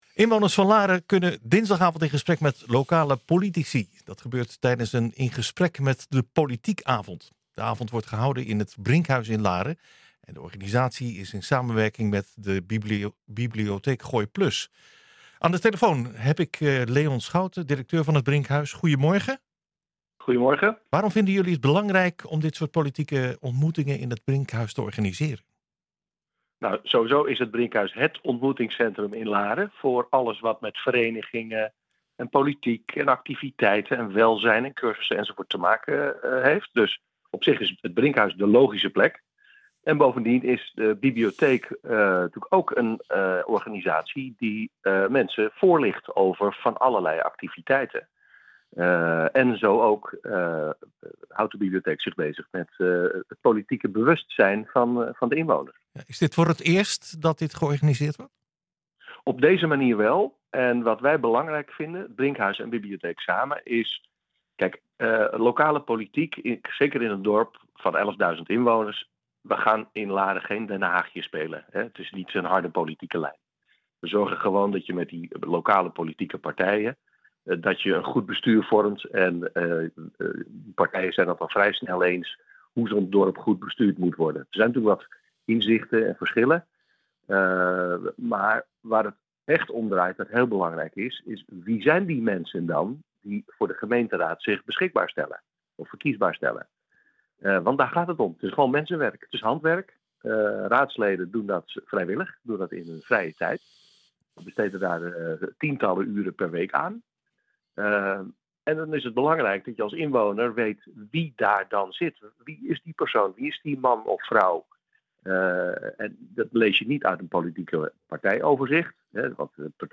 U luistert nu naar NH Gooi Zaterdag - Larense inwoners in gesprek met politieke partijen in het Brinkhuis
larense-inwoners-in-gesprek-met-politieke-partijen-in-het-brinkhuis.mp3